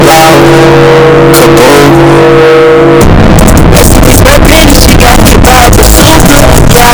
Bazooka Loud Asf Sound Effect Download: Instant Soundboard Button